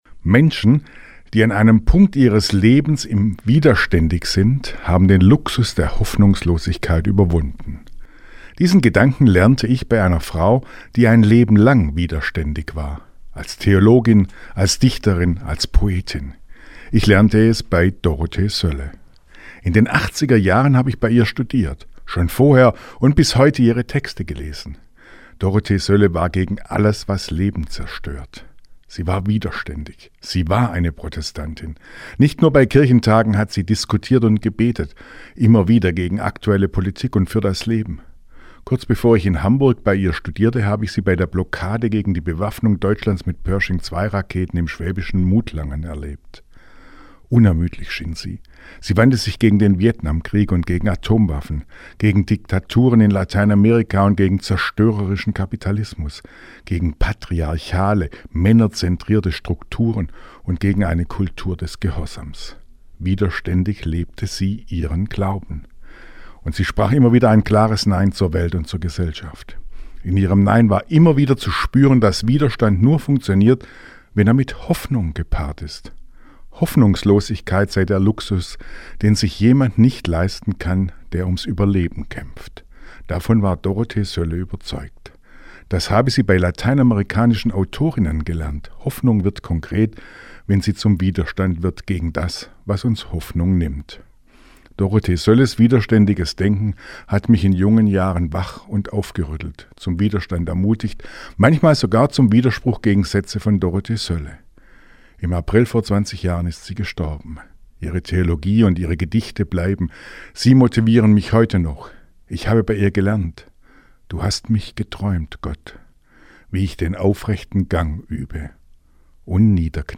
Radioandacht vom 25. April